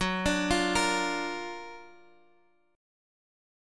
Gb+M7 chord